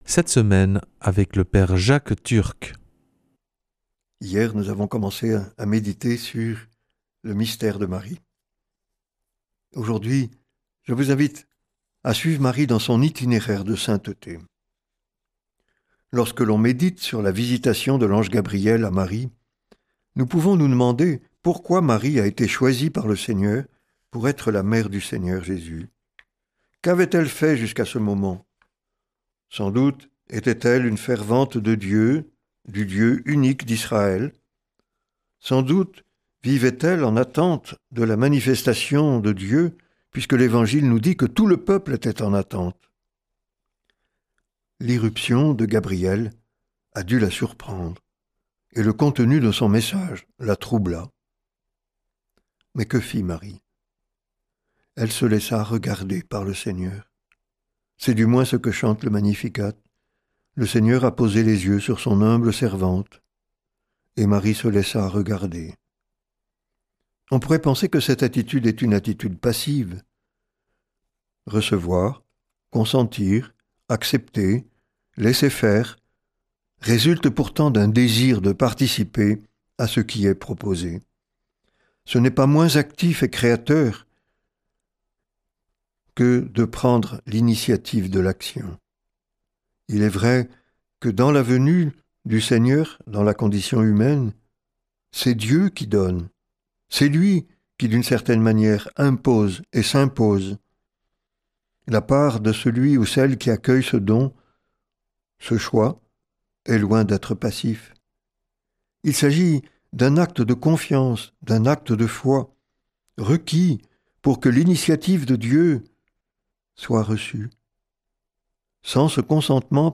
mardi 14 octobre 2025 Enseignement Marial Durée 10 min
Une émission présentée par